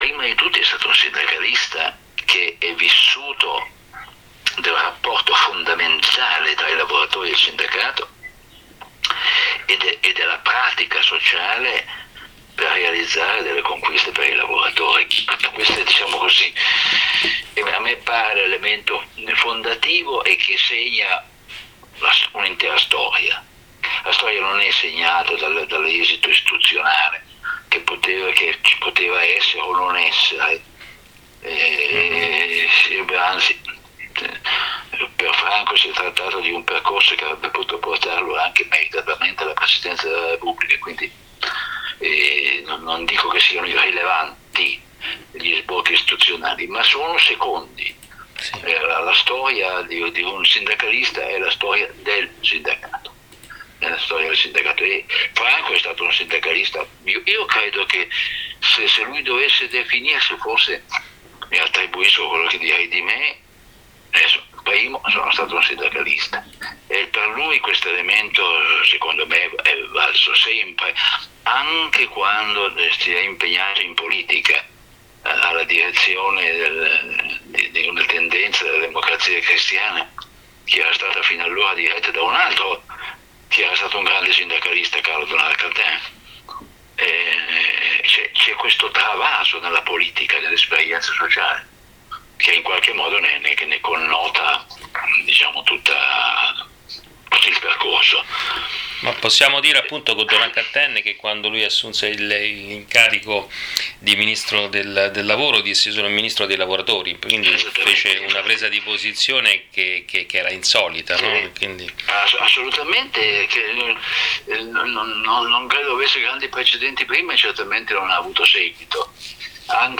Ascolta l’intervista integrale a Fausto Bertinotti sulla figura di Franco Marini.
Intervista-a-Fausto-Bertinotti-mp3.mp3